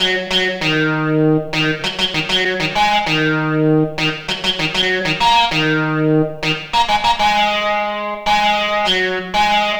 Rock Star - Guitar Lead.wav